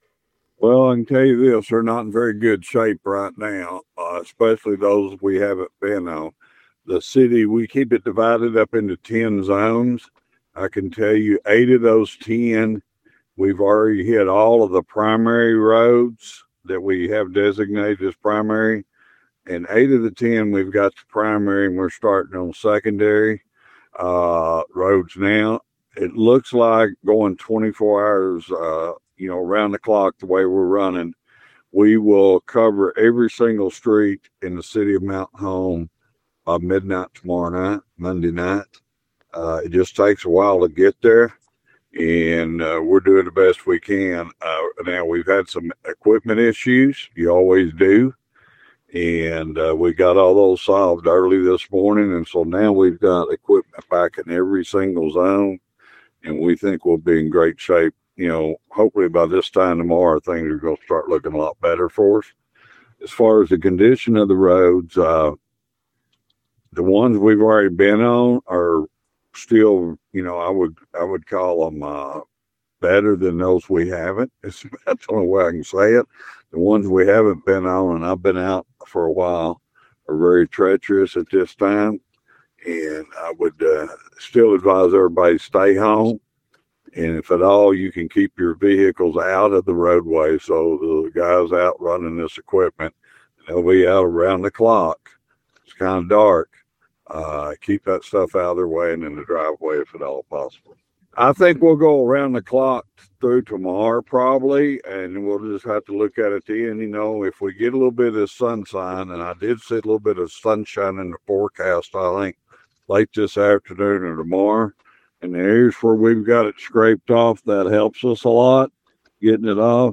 In his latest update to KTLO News, Mayor Adams says it may be until late Monday before crews are able to address all city streets.